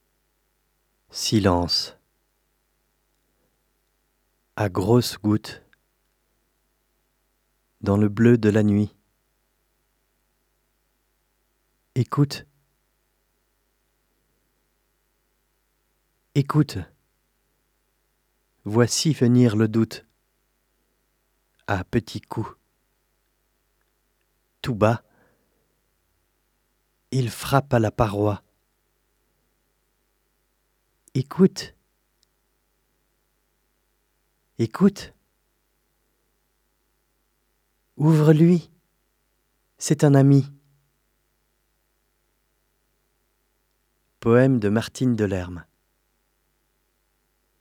SILENCE VOIX D'HOMME (RNS9)
L1_34_P_poeme_RNS9_silence_homme.mp3